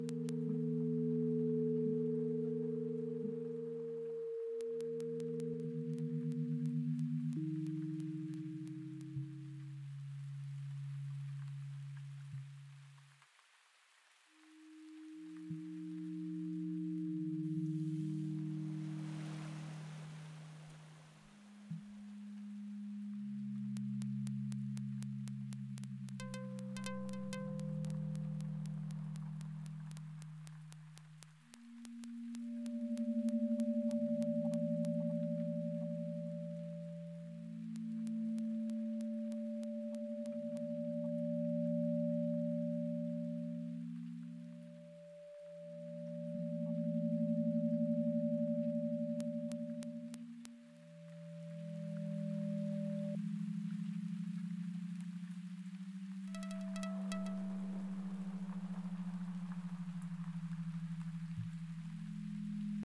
Eine grundsätzliche neue Art der Darstellung dieser Änderungssignale von Umweltdaten durch den Klimawandel wäre die algorithmische Aufbereitung der Daten in akustische Signale.
5) Demo Sounds 2024